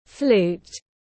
Cái sáo tiếng anh gọi là flute, phiên âm tiếng anh đọc là /fluːt/